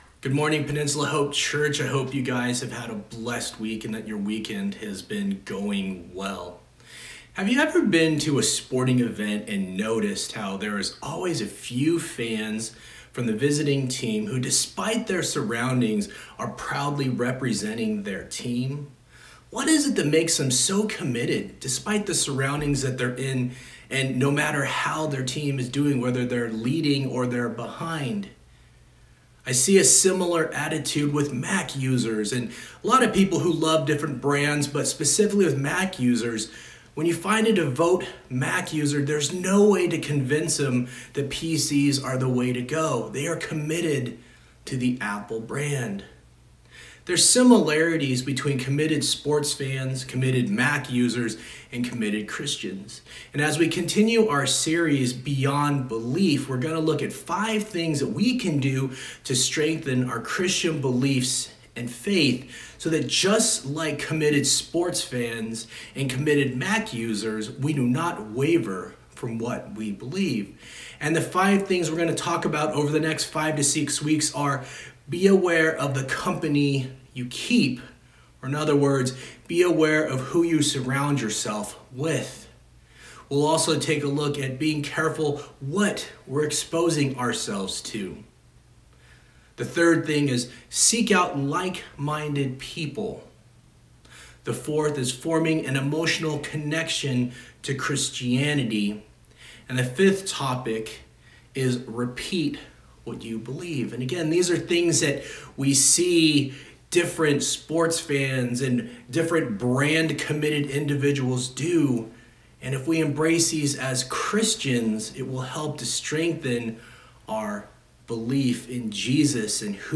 August 9, 2020 Sunday Message